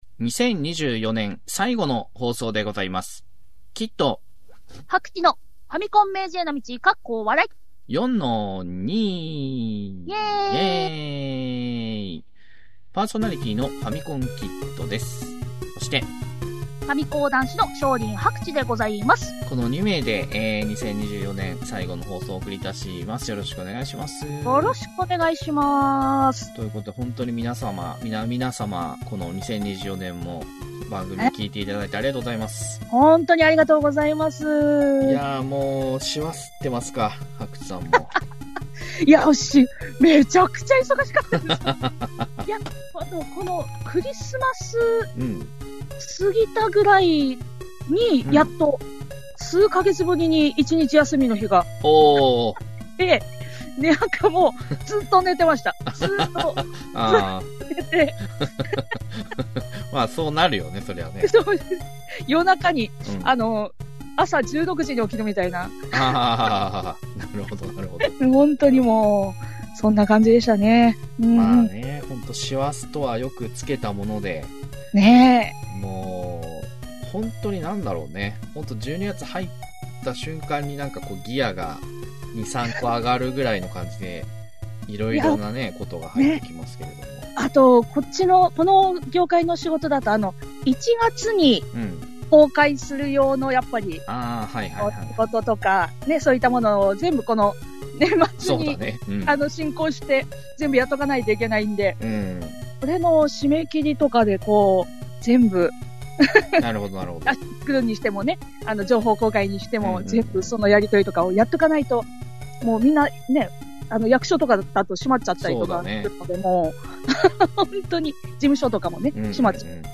昭和っぽいテイストのインターネットラジオへようこそ！